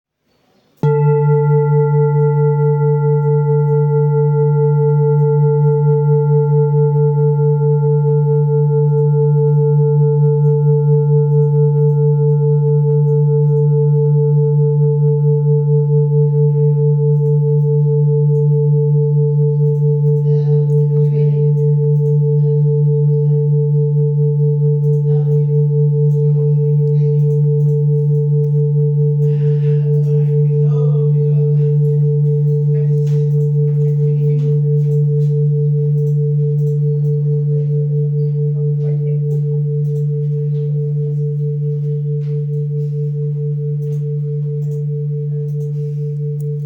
Singing Bowl, Buddhist Hand Beaten, with Fine Etching Carving, Select Accessories
Material Seven Bronze Metal
When played, the bowls produce a rich, harmonious sound that is said to stimulate the chakras and bring balance to the body's energy centers.